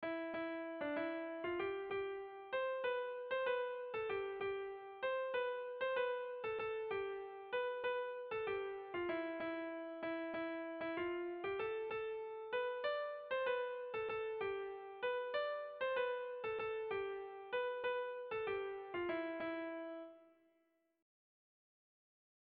Irrizkoa
Zortziko txikia (hg) / Lau puntuko txikia (ip)
ABDE